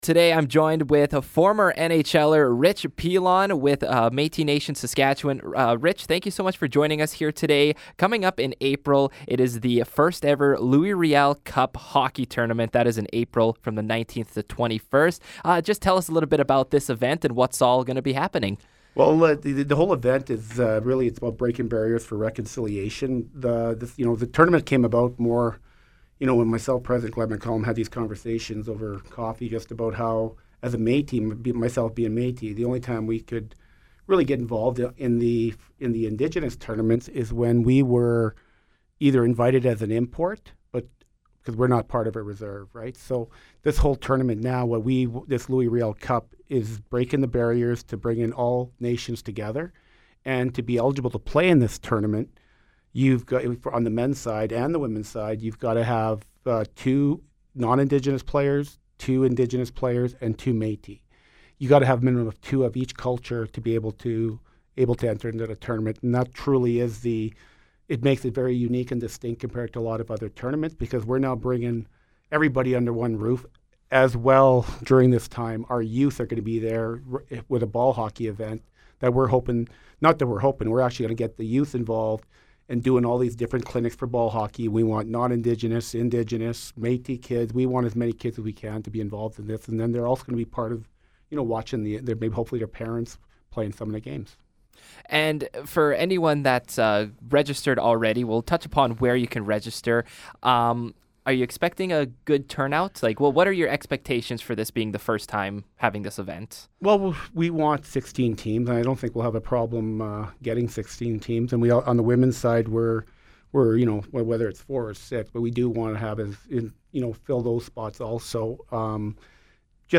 Interview: Rich Pilon with Metis Nation Saskatchewan